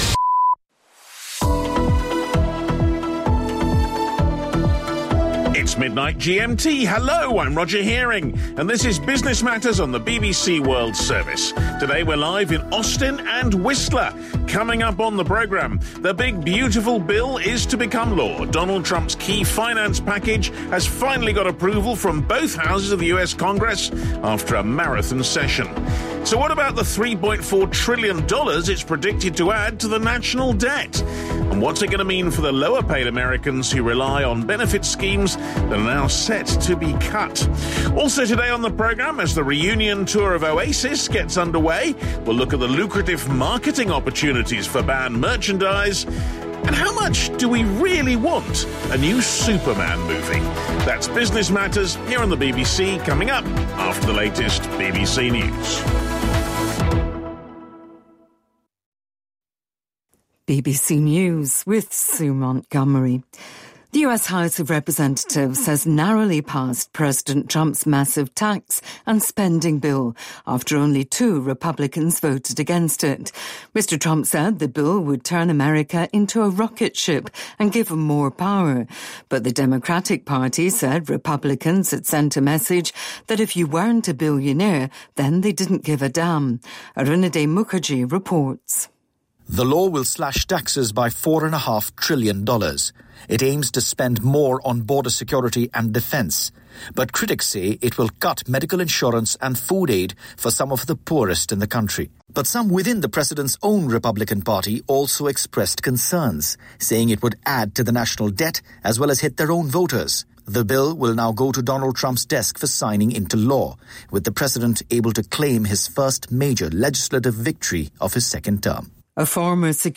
BBC新闻